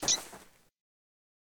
PixelPerfectionCE/assets/minecraft/sounds/mob/rabbit/hop1.ogg at mc116